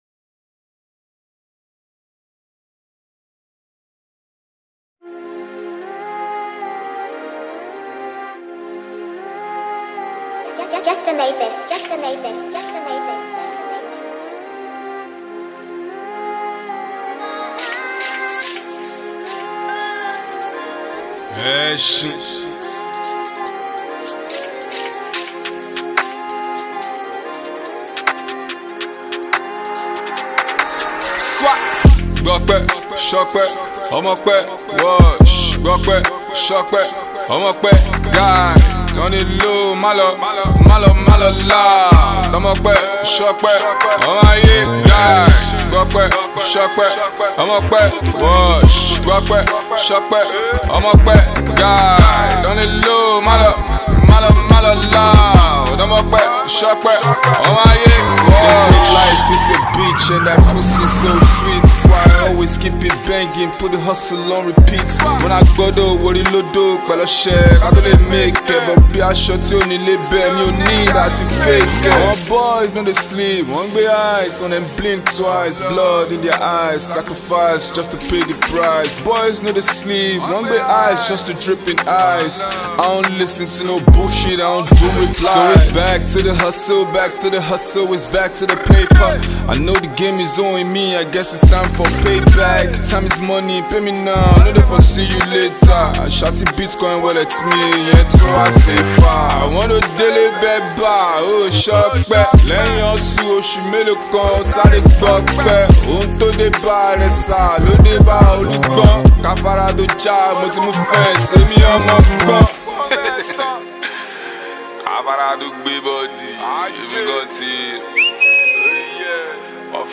Nigerian Indigenous Rapper